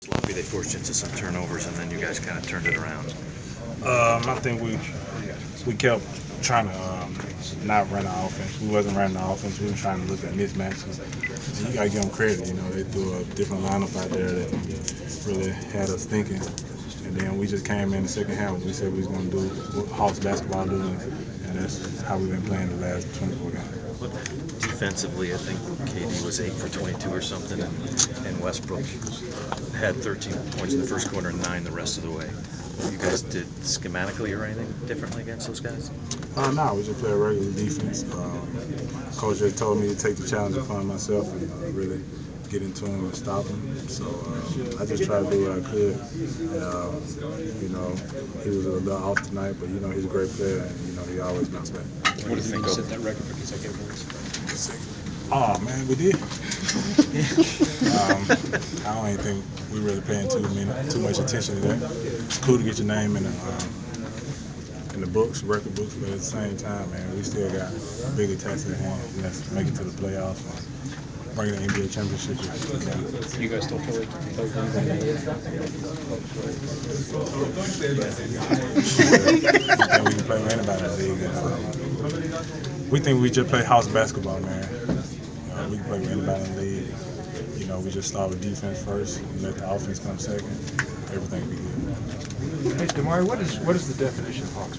Inside the Inquirer: Postgame presser with Atlanta Hawk DeMarre Carroll (1/23/15)
We caught up with Atlanta Hawks’ forward DeMarre Carroll following his team’s 103-93 win over the Oklahoma City Thunder on Jan. 23. The win was the Hawks’ 15th win in a row, a franchise record.